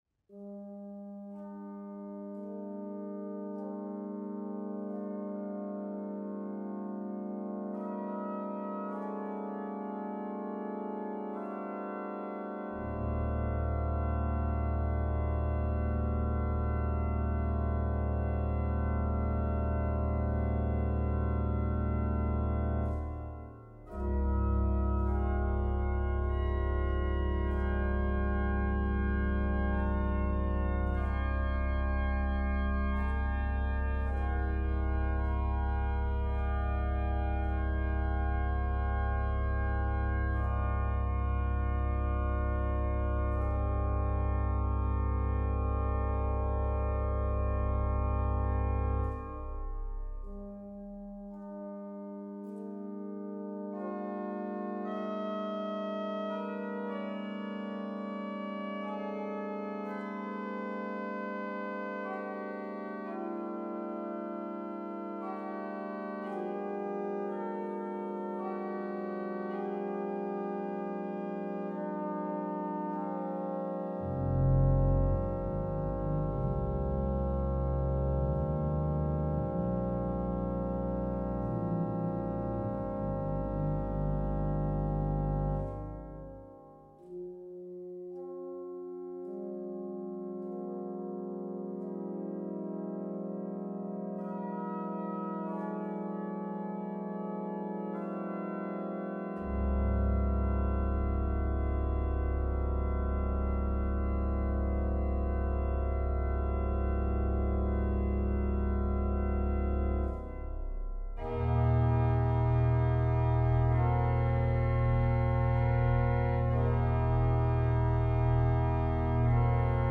organ Duration
It is an atmospheric and mystical contemplation.